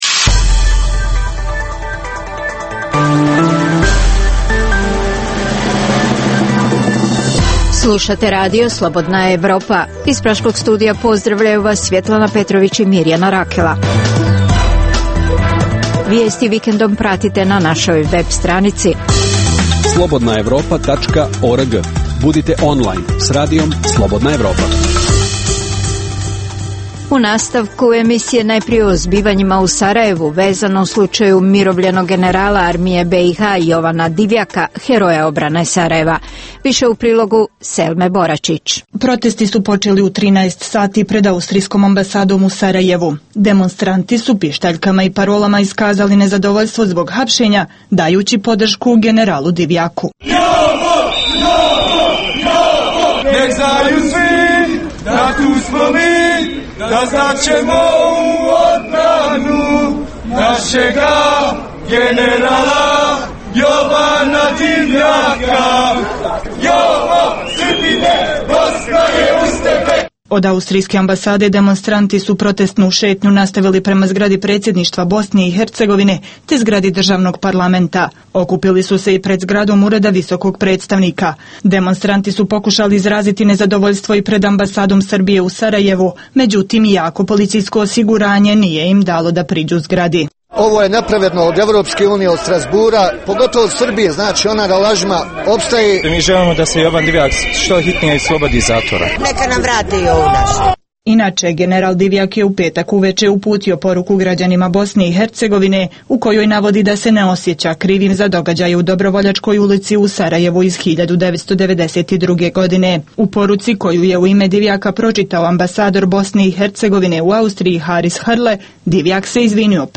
Emisija o dešavanjima u regionu (BiH, Srbija, Kosovo, Crna Gora, Hrvatska) i svijetu. Prvih pola sata emisije sadrži regionalne i vijesti iz svijeta, te temu sedmice u kojoj se analitički obrađuju najaktuelnije i najzanimljivije teme o dešavanjima u zemljama regiona. Preostalih pola sata emisije, nazvanih "Tema sedmice" sadrži analitičke teme, intervjue i priče iz života, te rubriku "Dnevnik", koji za Radio Slobodna Evropa vode poznate osobe iz regiona.